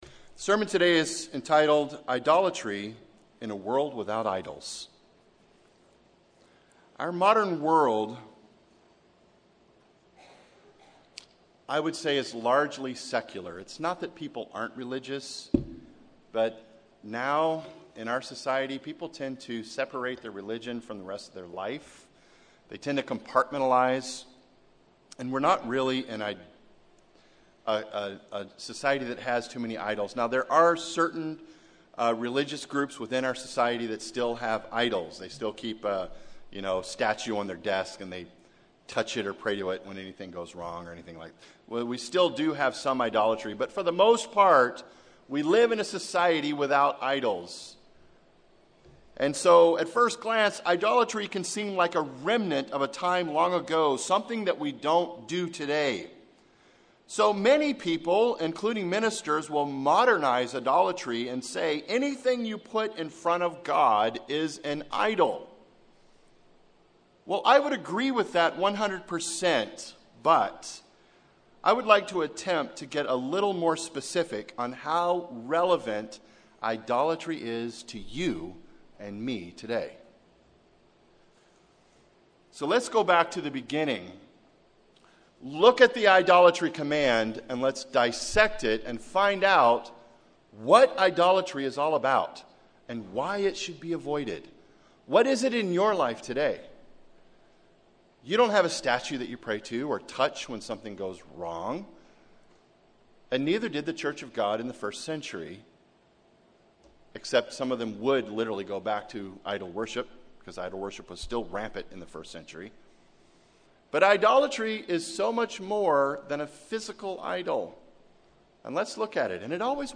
Often we put things in front of God but this sermon will get to what it is and what it means to us today. How relevant in idolatry to us today?